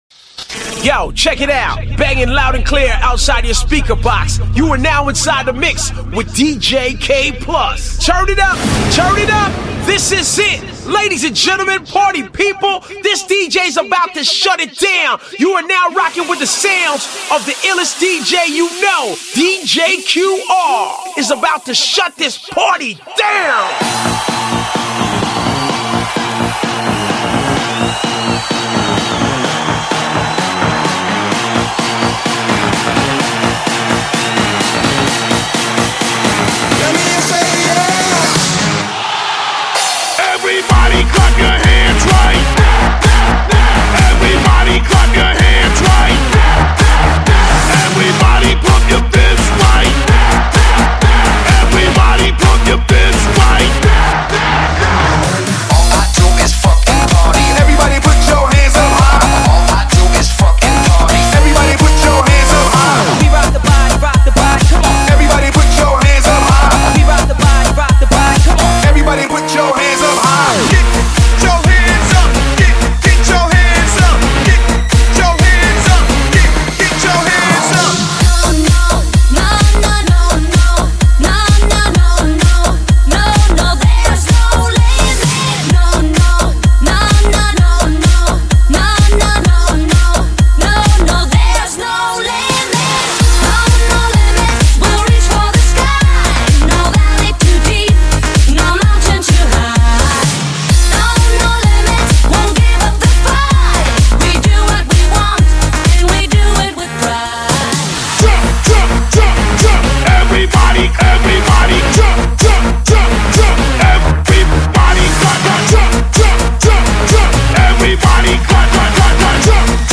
慢摇DJ
超重低音舞曲
最劲爆节奏
全新营造最动感节奏声效
TECHNO/LOUNGE/HOUSE/MINIMAL/DUB
特备超逼真临场动态声效